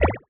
etfx_shoot_bubble.wav